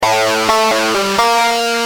Lead_a4.wav